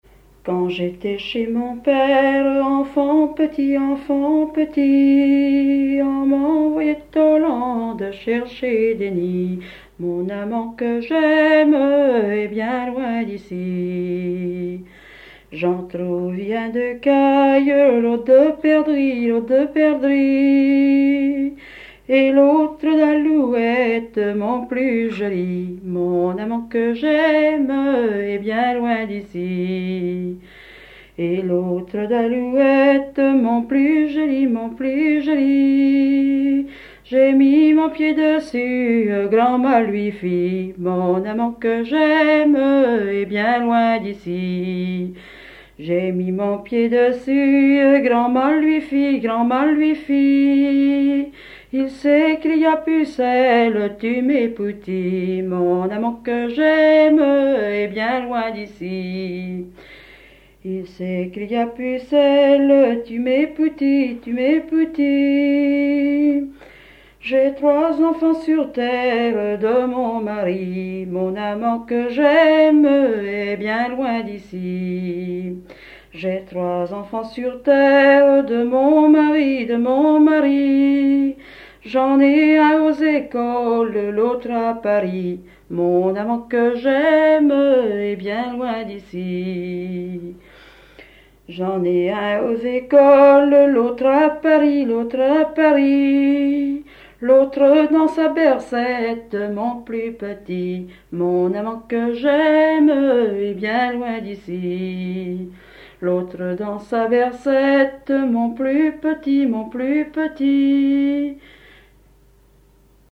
danse : ronde
Chansons traditionnelles
Pièce musicale inédite